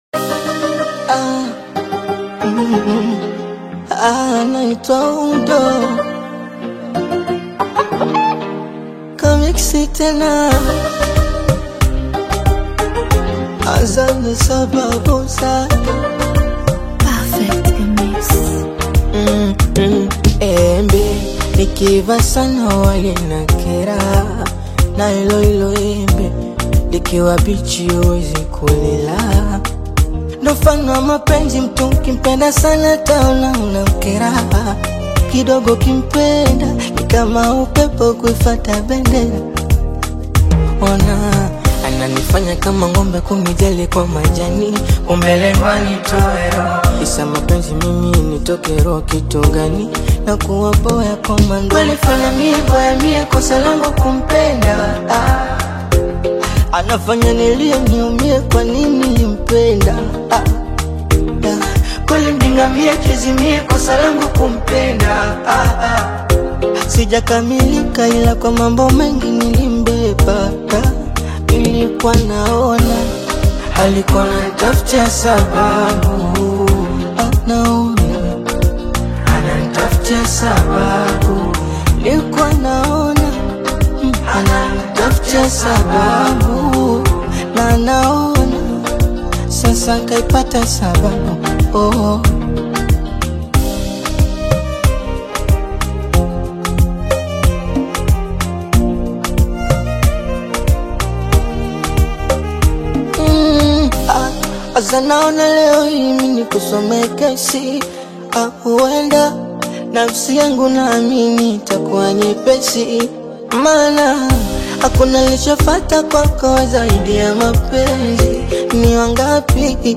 is a heartfelt track that dives deep into themes of love
Through smooth vocals and a well-crafted instrumental